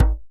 DJEM.HIT06.wav